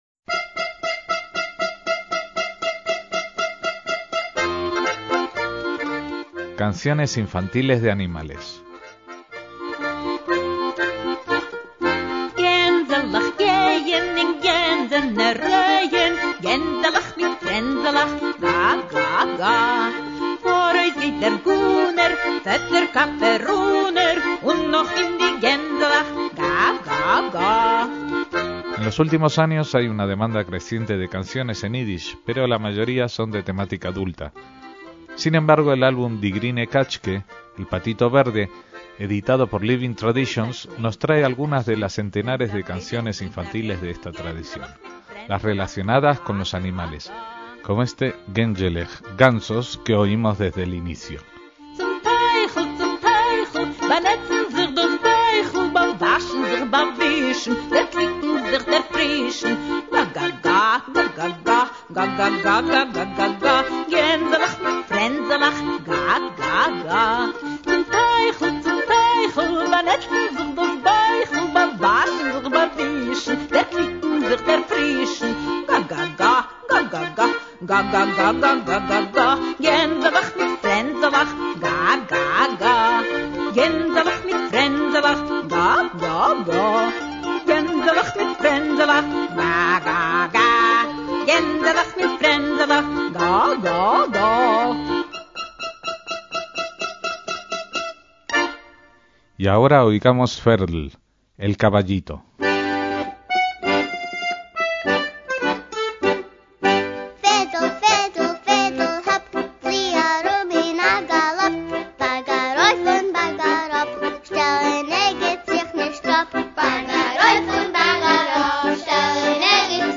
MÚSICA ÍDISH
25 canciones infantiles en ídish
Una música sencilla, melódica y espiritual